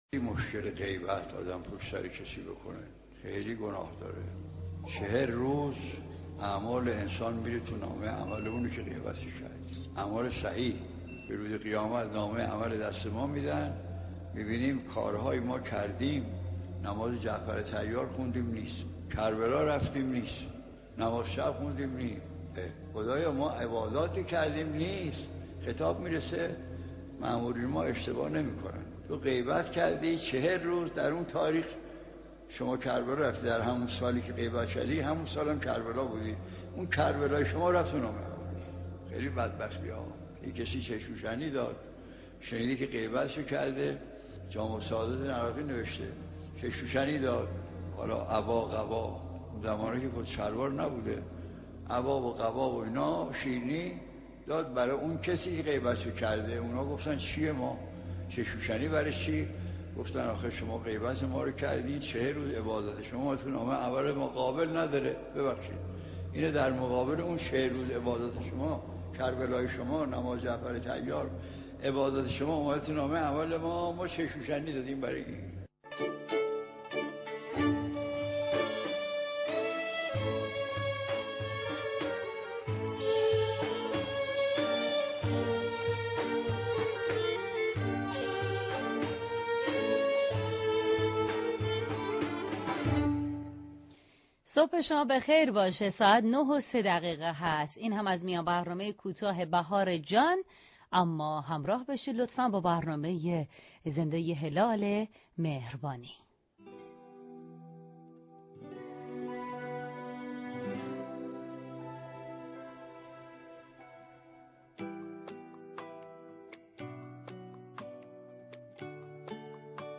مشروح اخــــبار